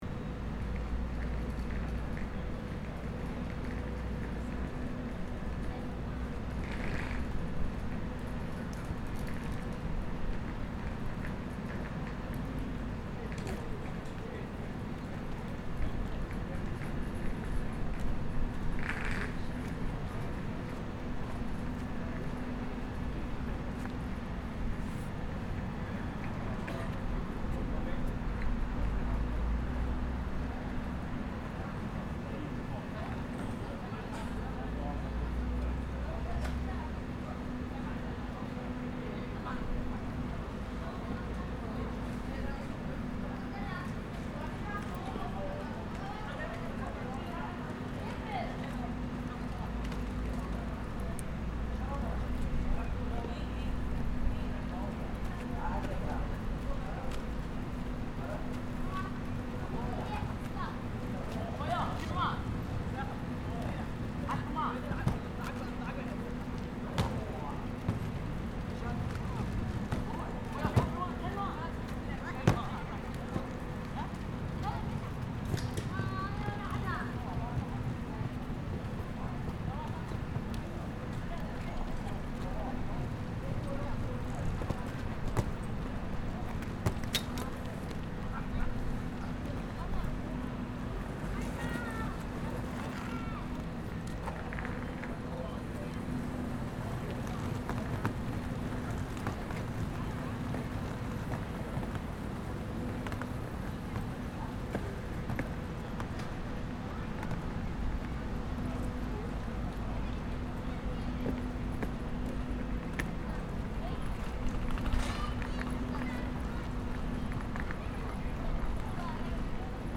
Soundscapes of Norrköping (3)
The road from the roundabout can be followed straight down and then one appears at Norrköping train station. The station is mid-sized with about 5 different tracks and plattforms for trains and passengers.
Trains, a train horn, carry-on bags, road barriers being lowered…